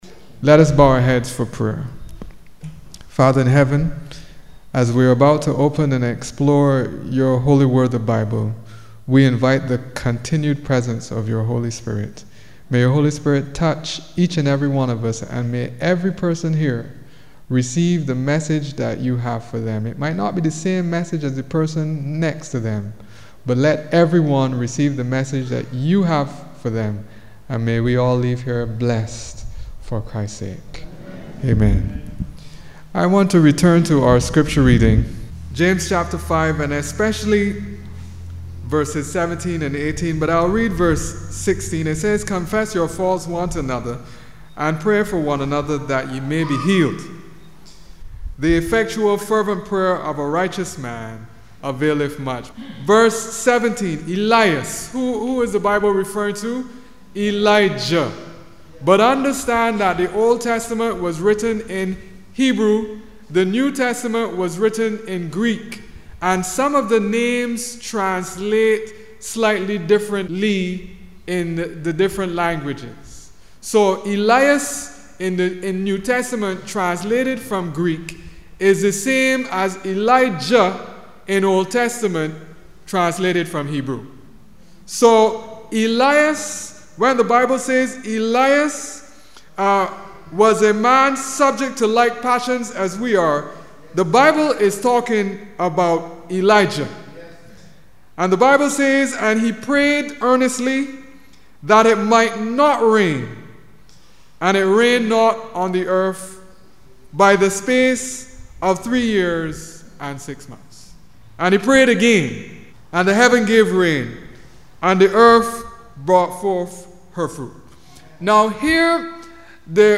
Sunday_Night_Worship_Service_-_Jan16-2011.mp3